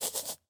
Minecraft Version Minecraft Version snapshot Latest Release | Latest Snapshot snapshot / assets / minecraft / sounds / mob / fox / sniff4.ogg Compare With Compare With Latest Release | Latest Snapshot
sniff4.ogg